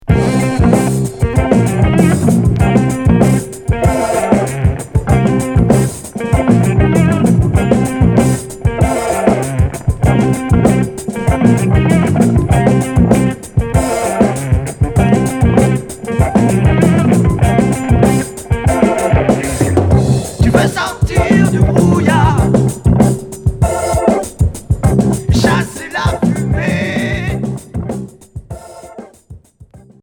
Heavy groove Premier 45t retour à l'accueil